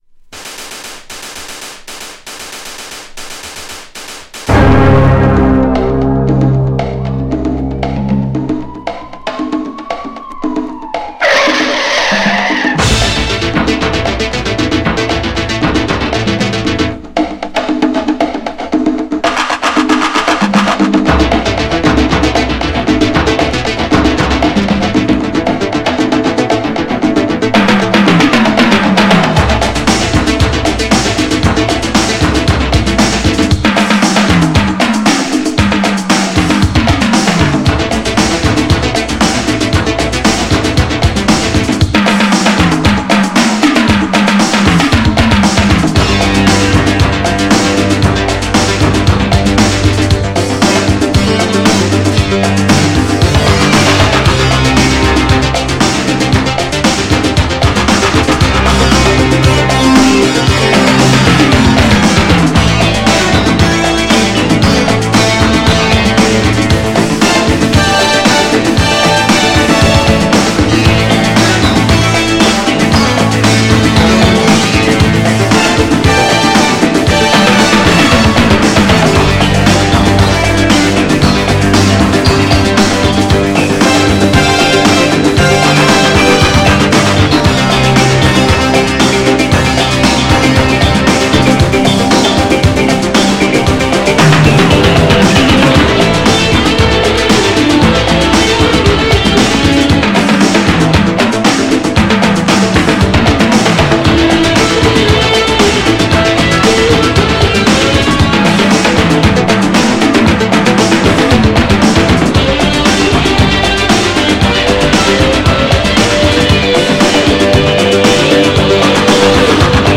GENRE Dance Classic
BPM 91〜95BPM
INSTRUMENTAL
アンビエント
ダウンテンポ # ニューウェーブ
妖艶